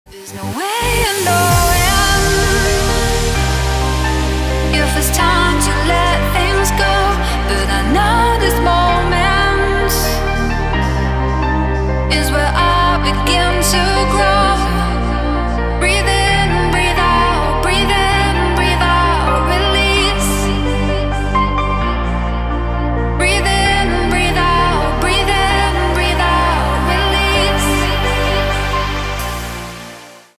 • Качество: 256, Stereo
громкие
мелодичные
клавишные
пианино
красивый женский голос
progressive trance